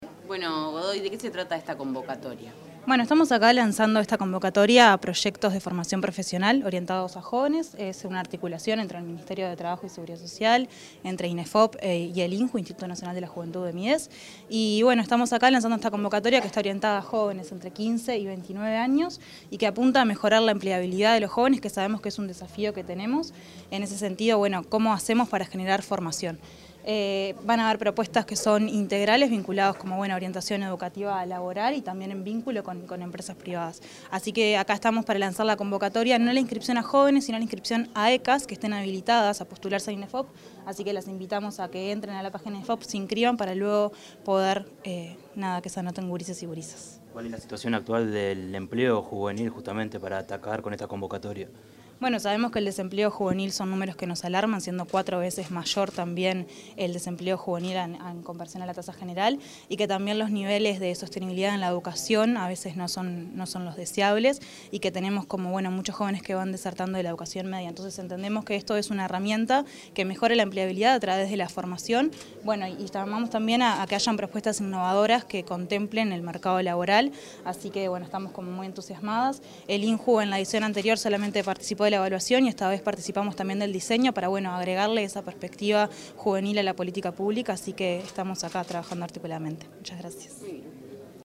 Declaraciones de la directora del INJU, Eugenia Godoy
Declaraciones de la directora del INJU, Eugenia Godoy 15/09/2025 Compartir Facebook X Copiar enlace WhatsApp LinkedIn Tras la presentación de una convocatoria de formación profesional, la directora del Instituto Nacional de la Juventud (INJU), Eugenia Godoy, brindó declaraciones a la prensa.